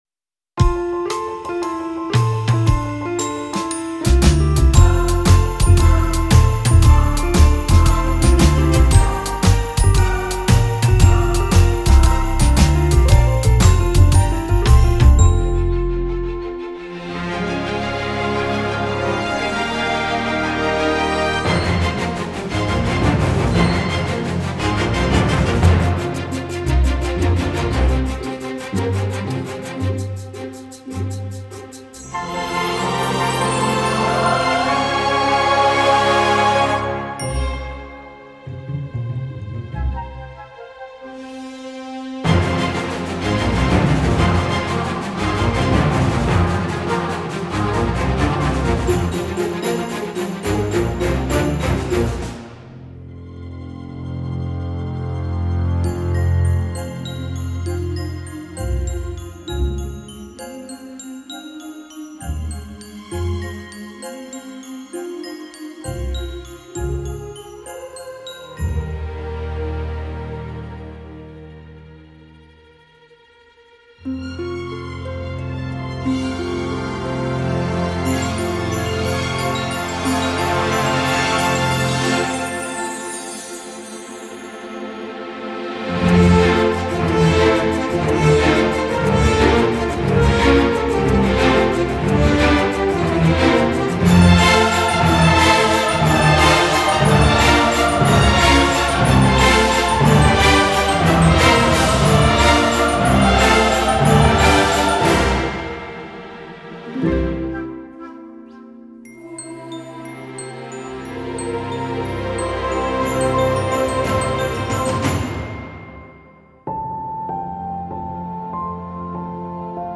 Genre: filmscore.